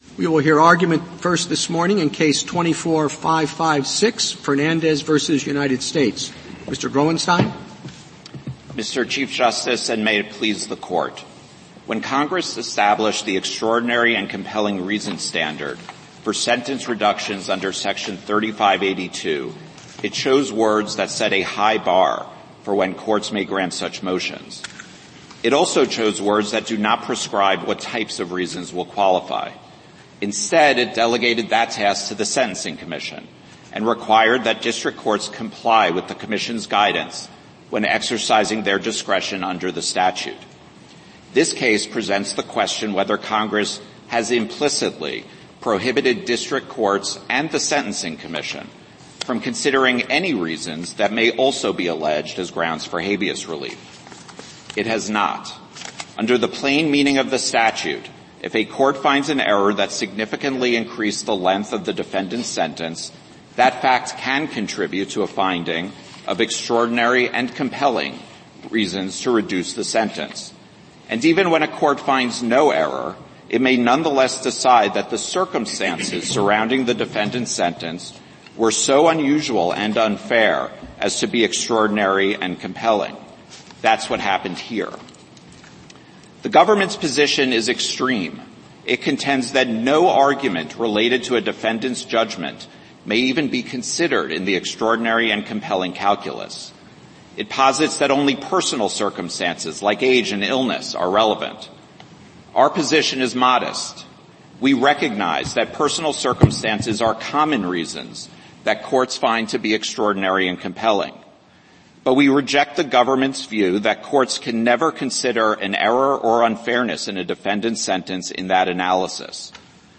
Supreme Court Oral Arguments · S2025